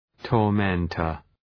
Προφορά
{tɔ:r’mentər}